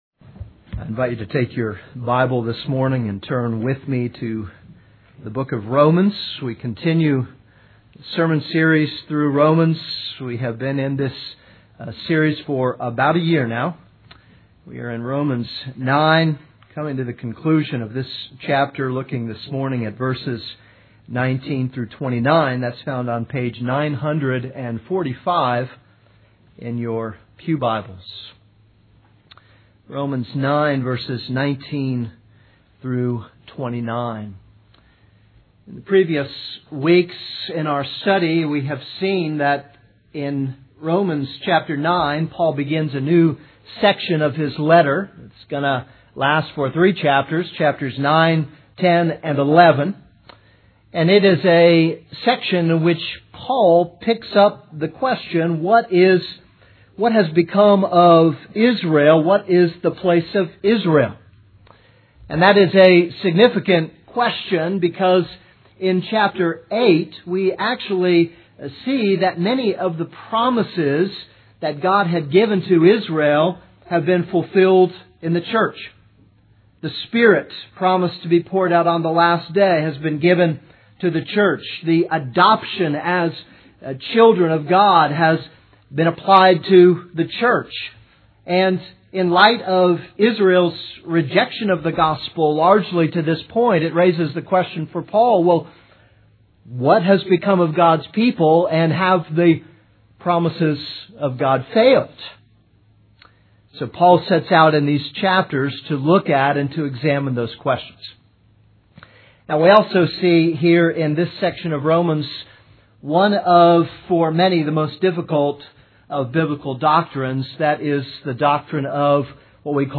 This is a sermon on Romans 9:19-29.